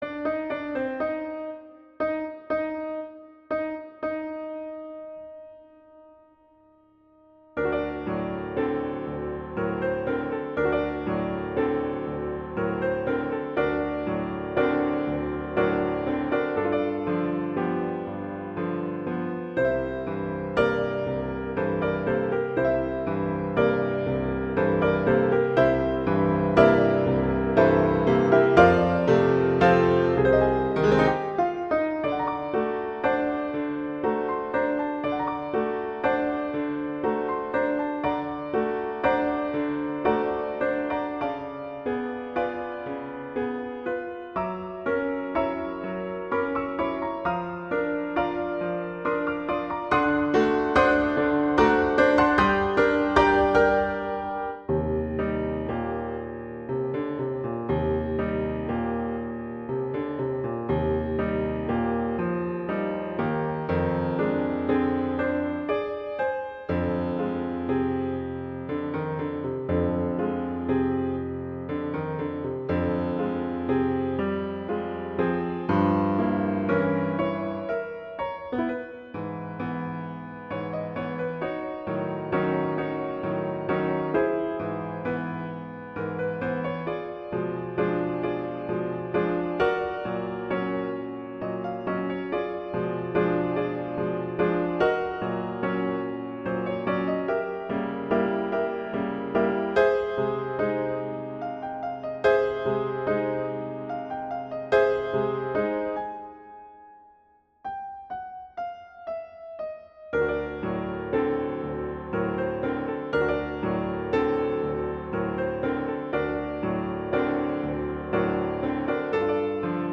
classical
G minor
♩=120 BPM